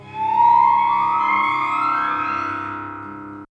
Source: Resonated artificial harmonic gliss (6:30-7:28)
Processing: granulated, amp. correlated at max. 35:1 + 3 lower harmonics
Res_Artificial_Gliss1.aiff